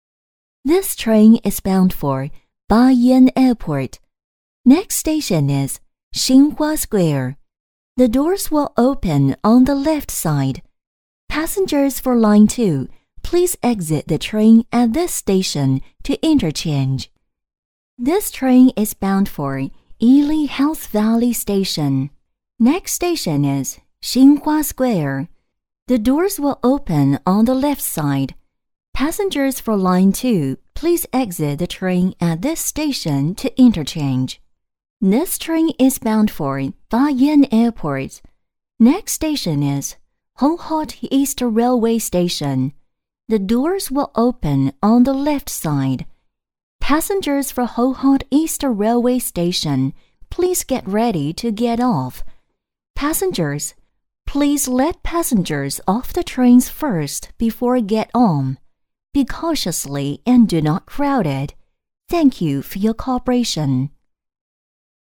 普通话
温暖 大气 自然 甜美 优雅